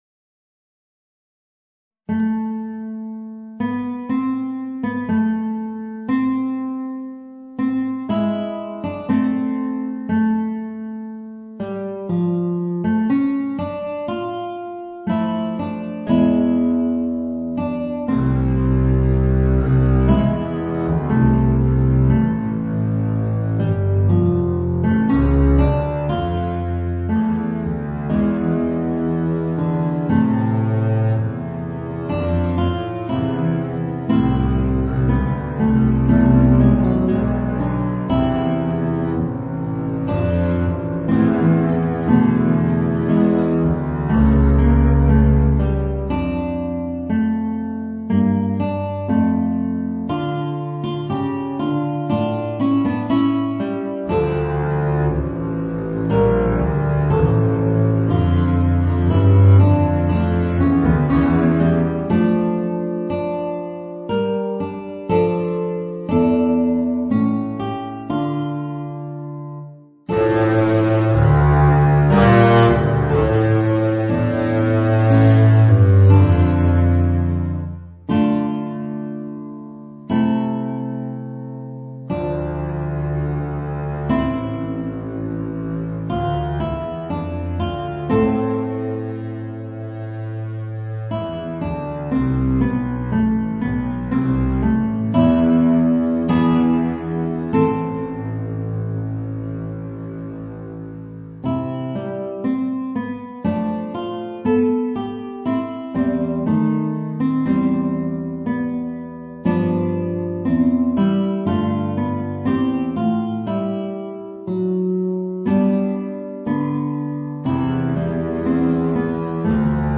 Voicing: Contrabass and Guitar